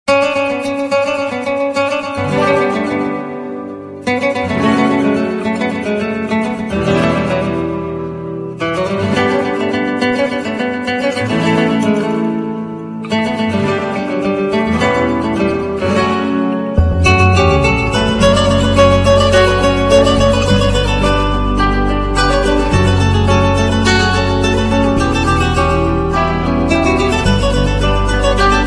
آهنگ غمگین زنگ تلفن